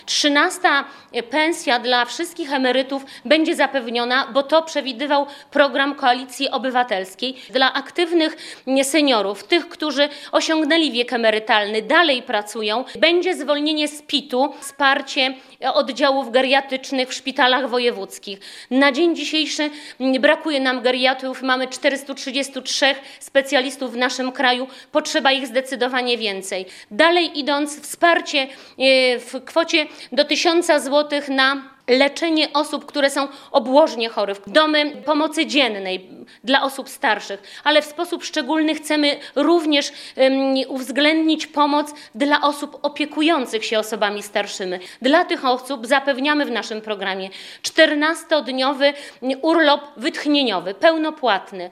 Utrzymanie 13 emerytury, zwolnienie z PIT-u, wsparcie oddziałów geriatrycznych w szpitalach wojewódzkich, domy pomocy dziennej dla osób starszych – to postulaty wyborcze Koalicji Obywatelskiej dla polskich seniorów. Plan poprawy sytuacji osób najstarszych w kraju przedstawiła w poniedziałek (30.09), na swojej konferencji prasowej Bożena Kamińska, kandydatka Koalicji do Sejmu.